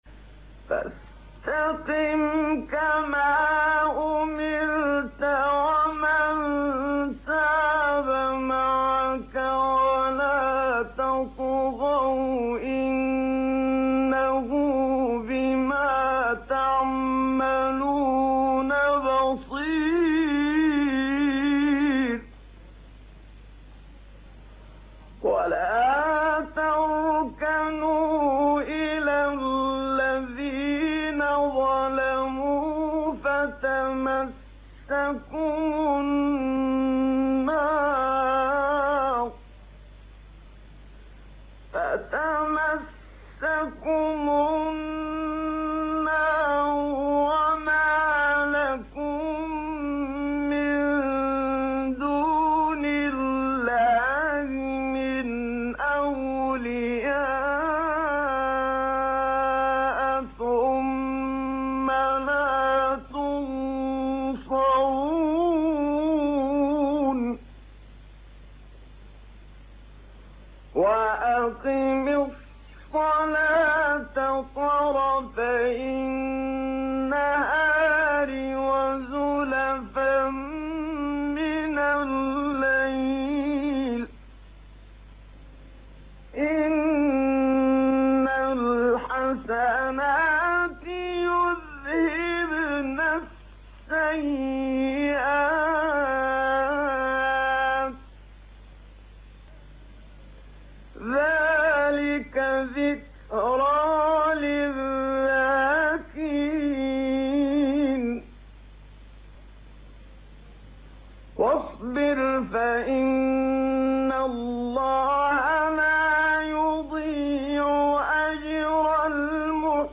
سایت-قران-کلام-نورانی-بیات-منشاوی-3.mp3